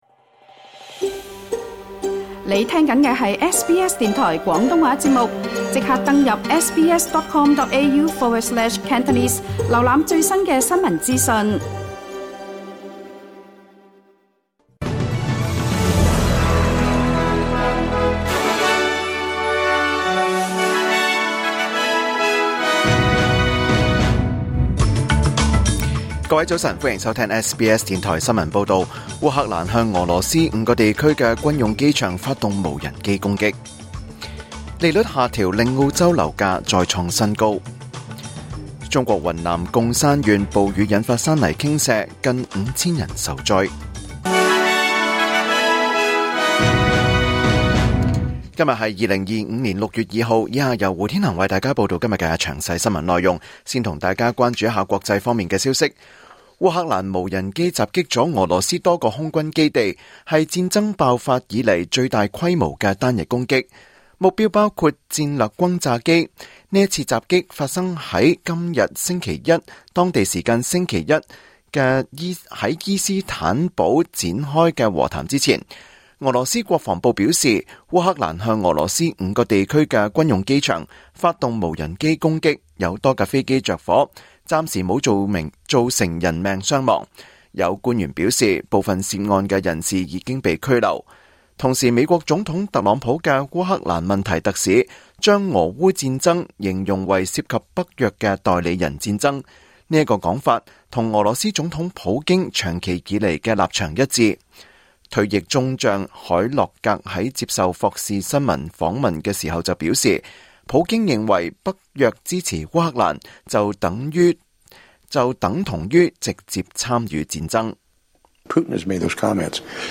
2025年6月2日SBS廣東話節目九點半新聞報道。